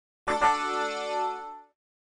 На этой странице собраны классические звуки Windows Vista — системные уведомления, мелодии запуска и завершения работы, а также другие аудиоэлементы ОС.
Tadaaa